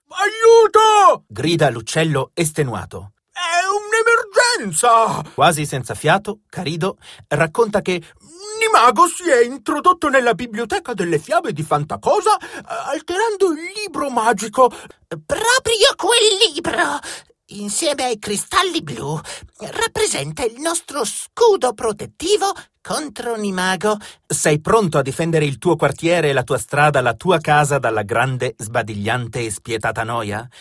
Hörspiel Italienisch (CH)
Tessin